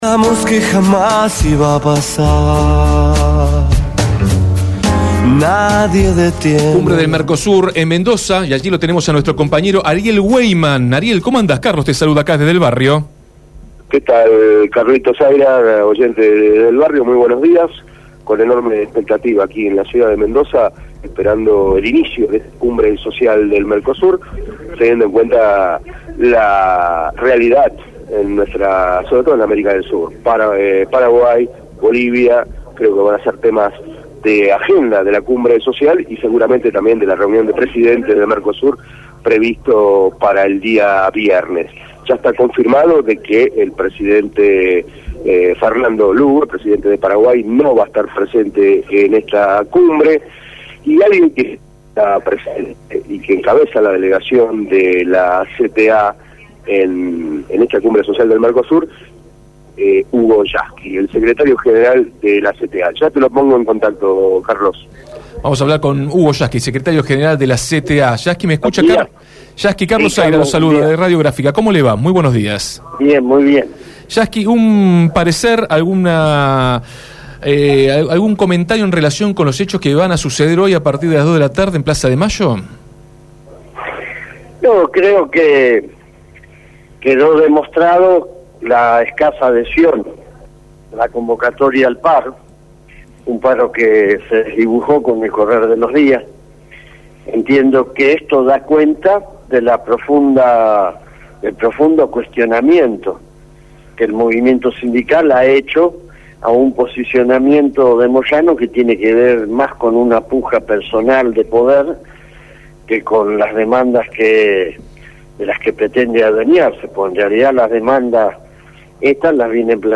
Acto de la CGT: Entrevista a Hugo Yasky
27-06-12 YASKY HUGO, CTA ,DESDE MENDOZA